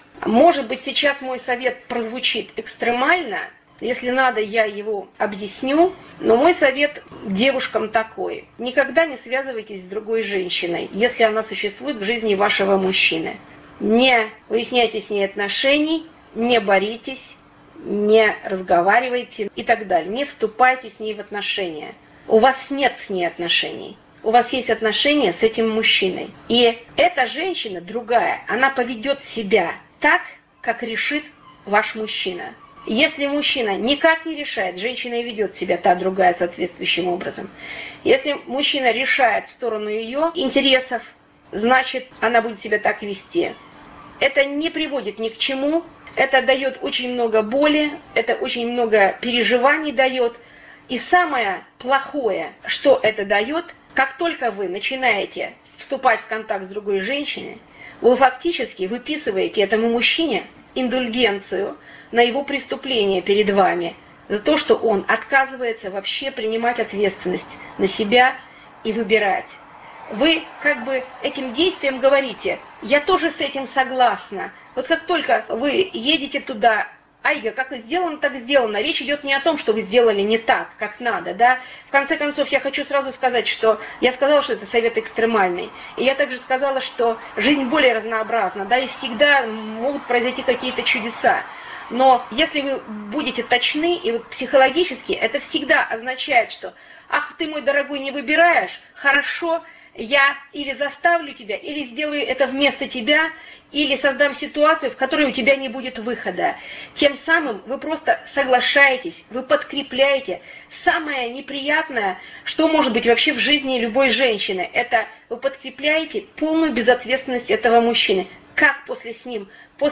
В 3-хминутном фрагменте Тренинга против критики слушайте мой экстремальный совет девушке, которая спрашивает: